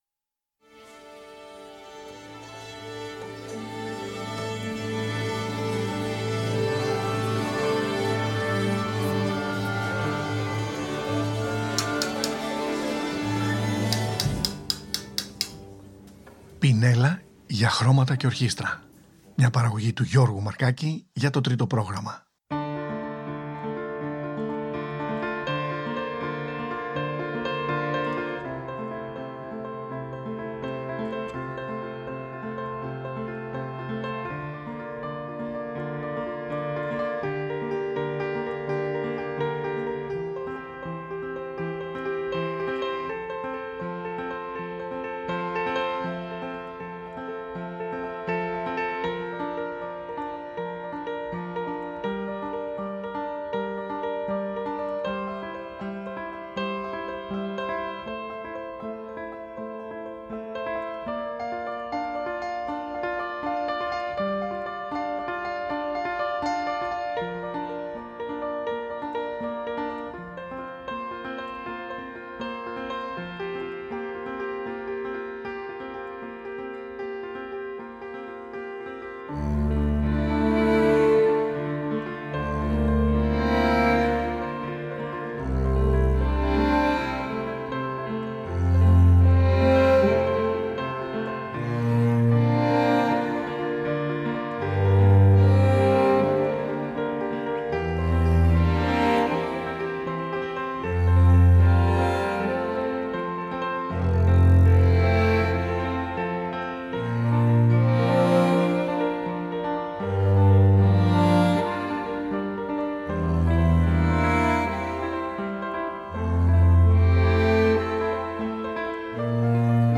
στη μαρίμπα
cello